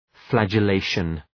Προφορά
{,flædʒə’leıʃən}